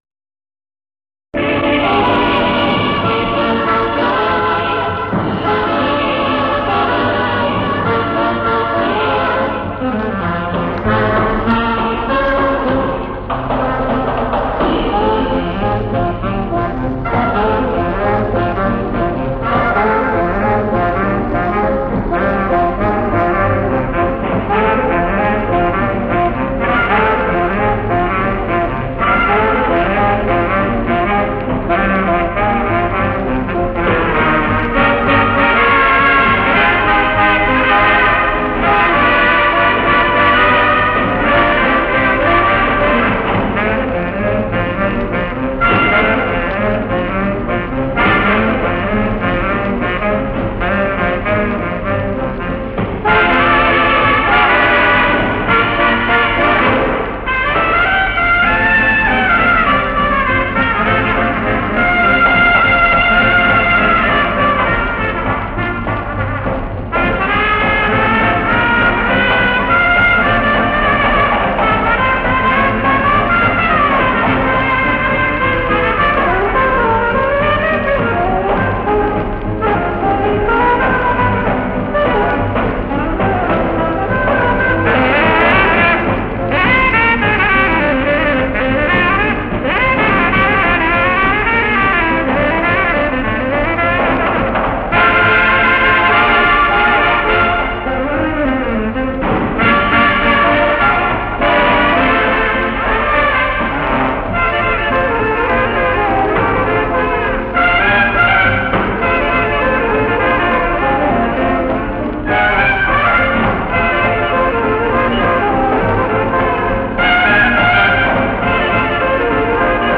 Complesso Musicale Crazy Boys nell'anno 1952 durante un concerto al teatro Politeama Bisceglie.
Prove tecniche di registrazione presso il Politeama Italia di Bisceglie su filo d'acciaio (ditta Radio Valls - Bisceglie)